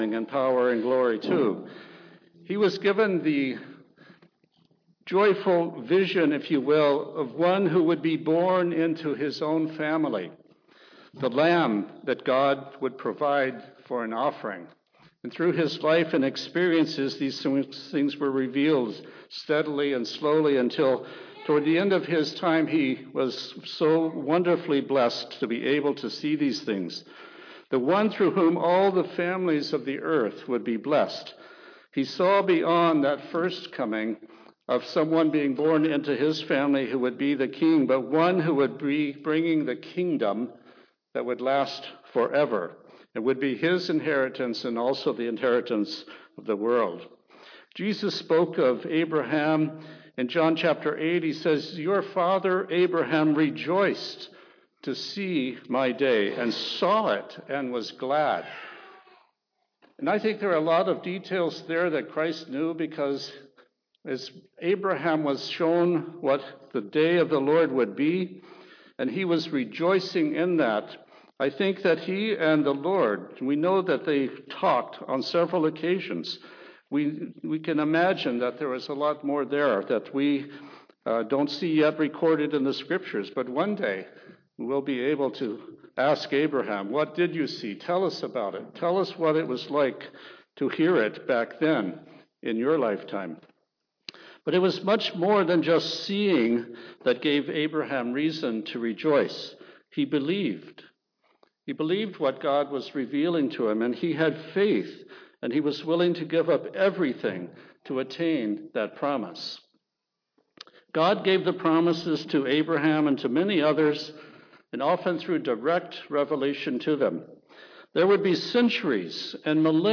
Sermons
Given in Olympia, WA Tacoma, WA